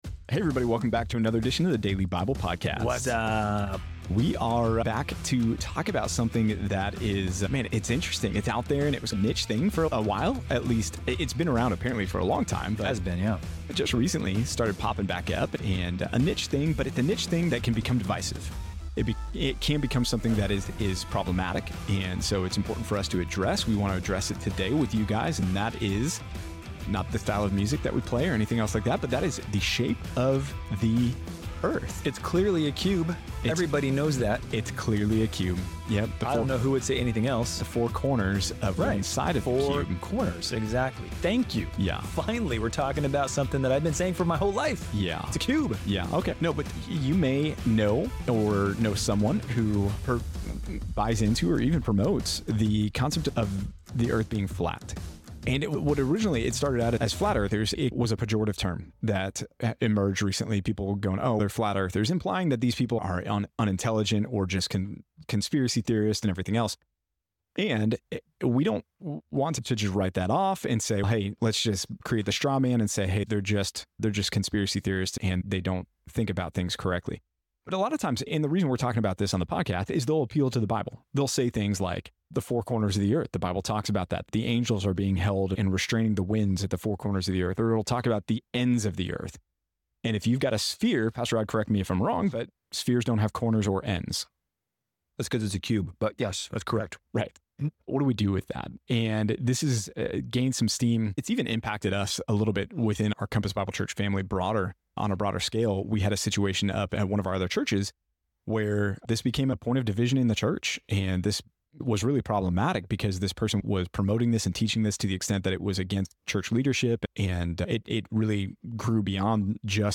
In this episode of the Daily Bible Podcast, the hosts delve into the divisive topic of flat earth theory, emphasizing the importance of understanding biblical poetic language. They explore how some people use scripture to justify flat earth claims and discuss the implications of taking poetic passages literally. The episode also includes a discussion on 1 Samuel 13-14, highlighting Jonathan's bravery in battle and King Saul's rash vow.